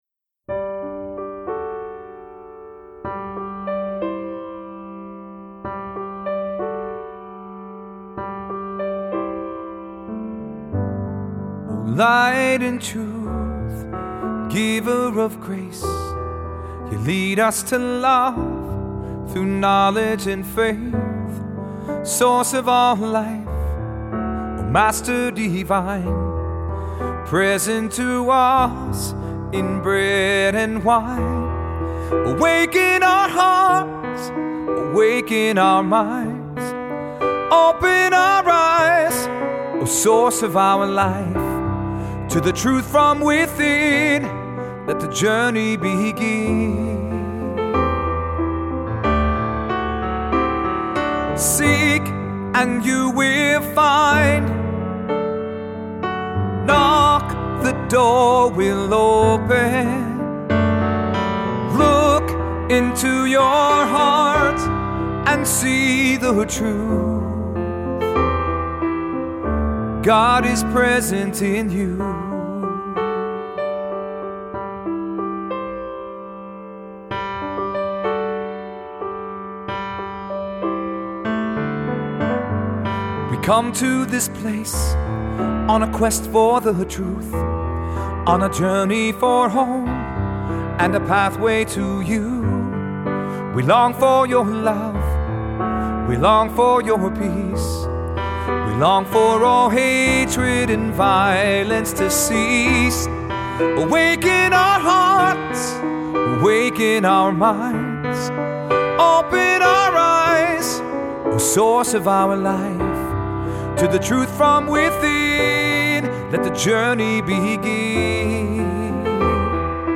Voicing: Solo; Assembly